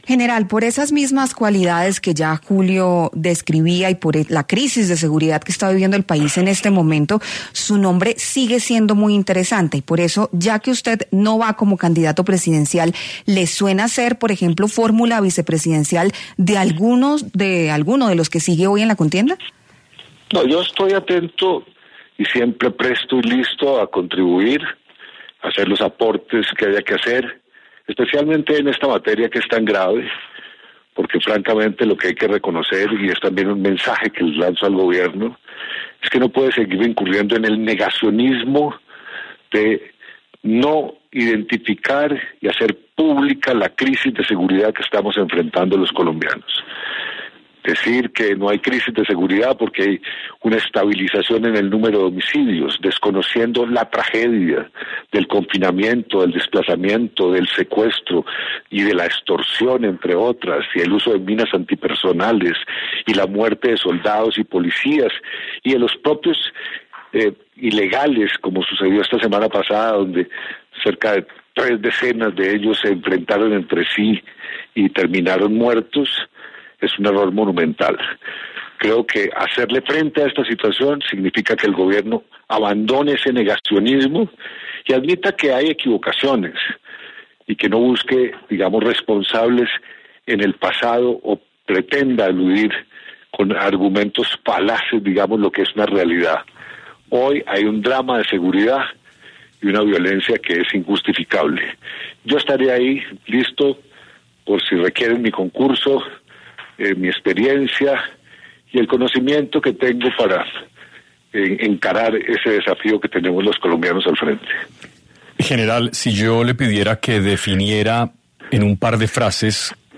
El general en retiro Óscar Naranjo estuvo en 6AM W, con Julio Sánchez Cristo, a propósito de su negativa de ser candidato presidencial para las elecciones de este año, pero también analizó lo que ha sido la política de Paz Total del Gobierno de Gustavo Petro.